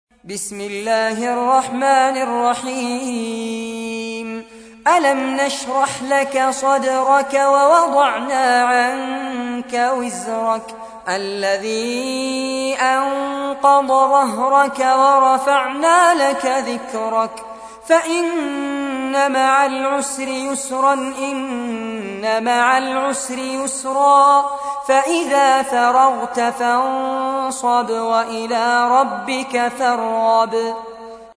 تحميل : 94. سورة الشرح / القارئ فارس عباد / القرآن الكريم / موقع يا حسين